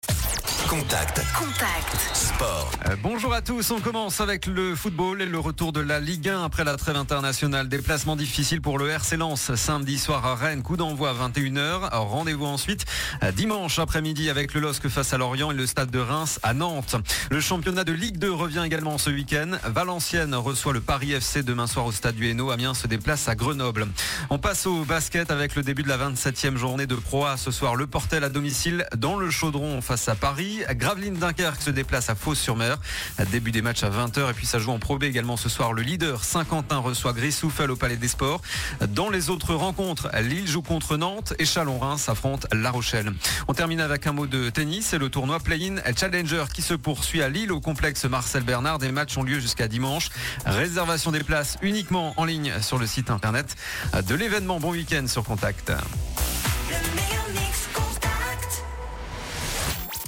Le journal des sports du 31 mars